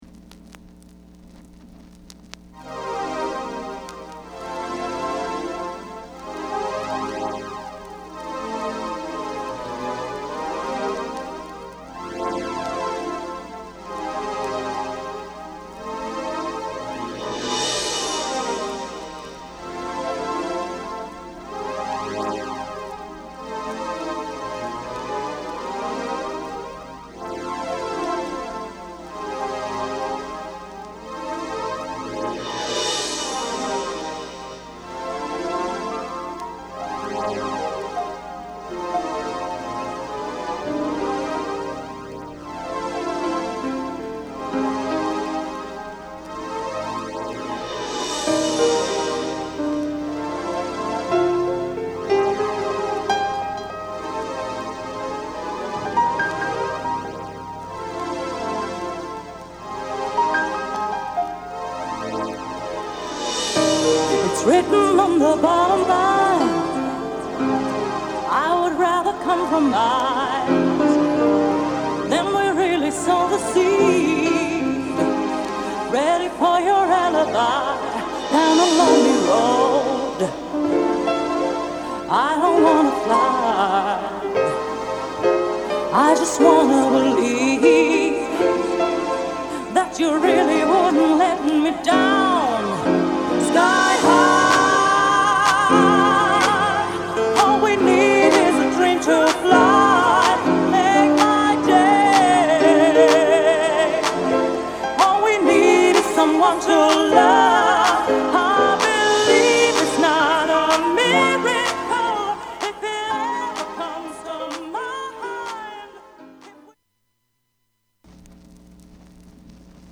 HOUSE /DANCE / SOUL